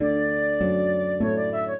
flute-harp
minuet0-9.wav